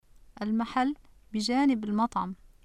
[al-maħall(-u) bi-jaanib-i l-maTʕam(-i)]